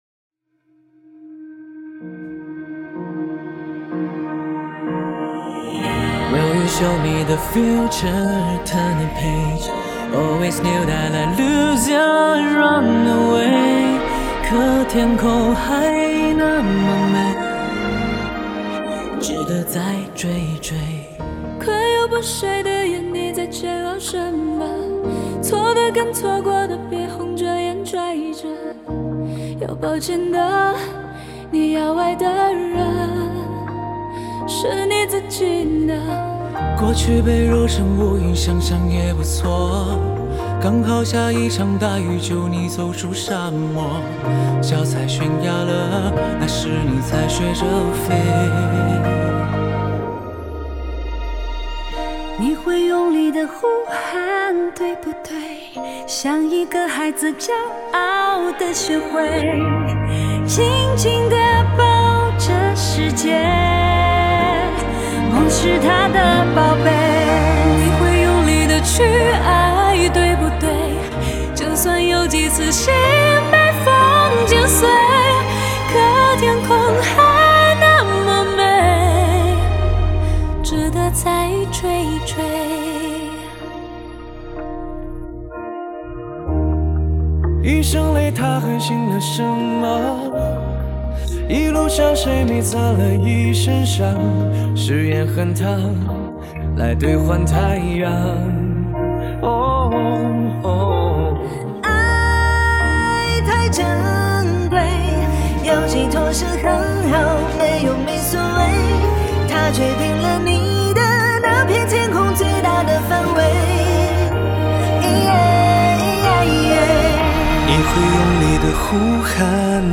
乐队/组合